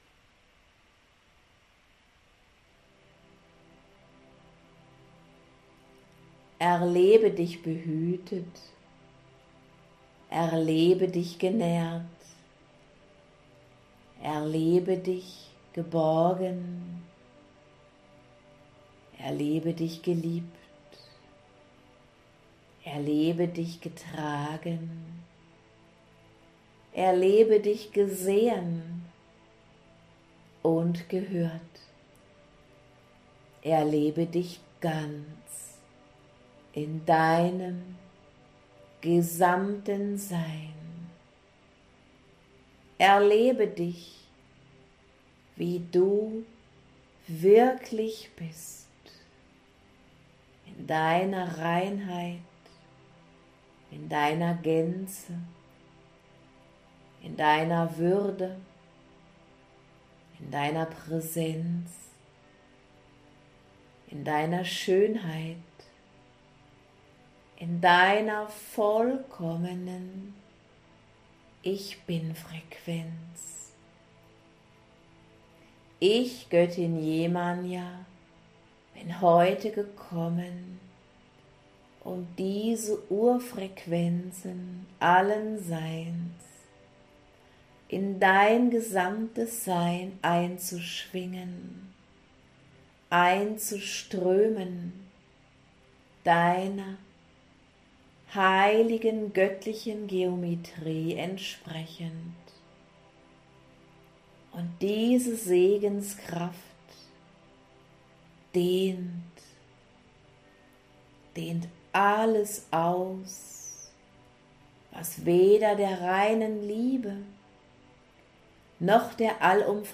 Göttin Yemanya und Gnadenengel Grace Channeling: „Freiheit statt Ego!“